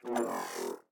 popup_jijia_down.ogg